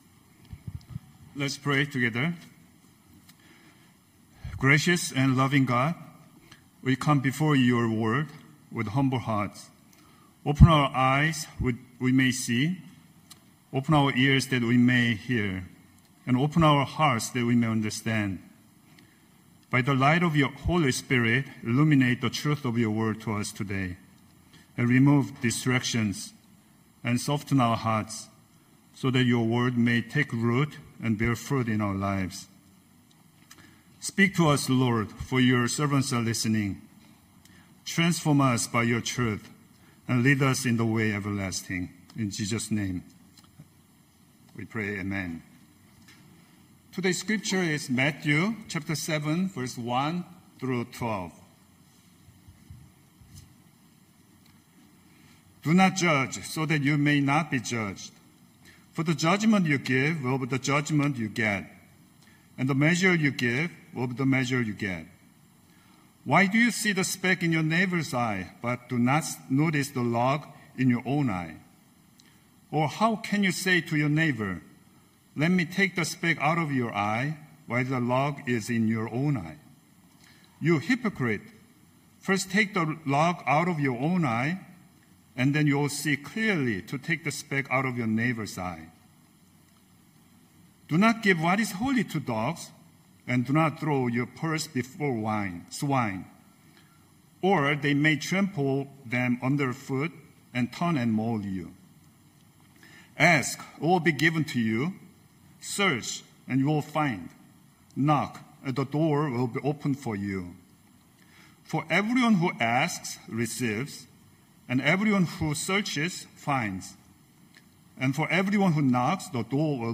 Knox Pasadena Sermons The Log Between Us Mar 22 2026 | 00:25:57 1x 00:00 / 00:25:57 Subscribe Share Spotify RSS Feed Share Link Embed